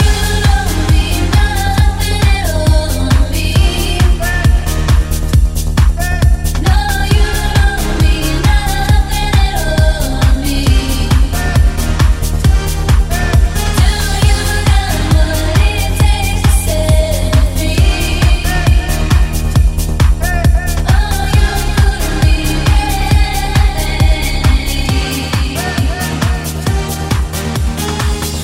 Genere: house,chill,deep,remix,hit